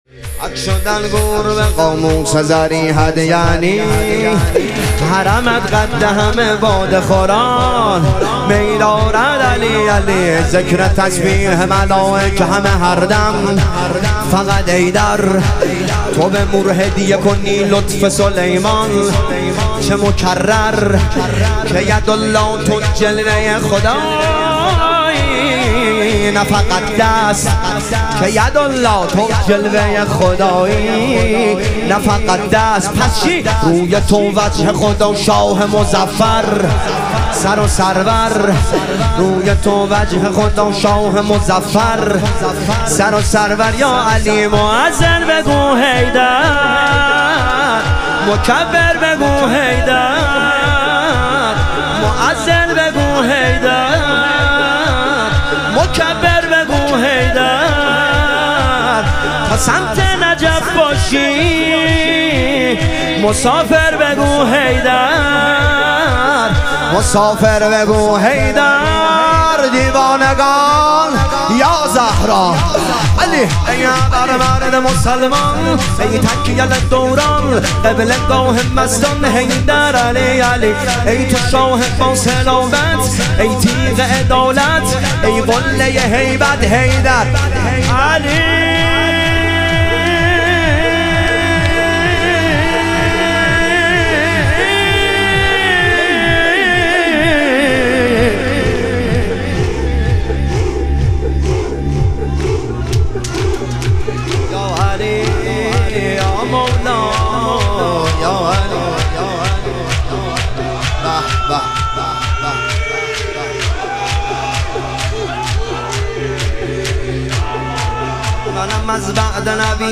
شهادت امام هادی علیه السلام - شور